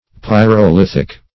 Search Result for " pyrolithic" : The Collaborative International Dictionary of English v.0.48: Pyrolithic \Pyr`o*lith"ic\, a. [Pyro- + lithic.]